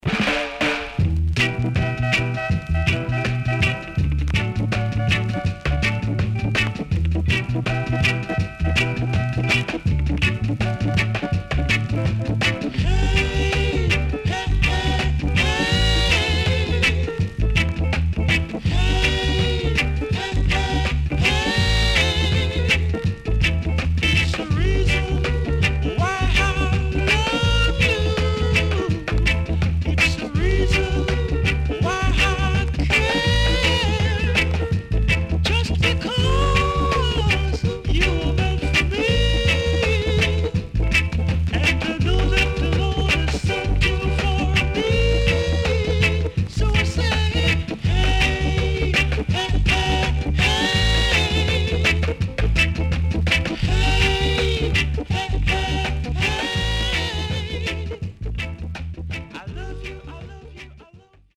HOME > Back Order [VINTAGE 7inch]  >  EARLY REGGAE
SIDE A:所々ジリジリしたノイズ入ります。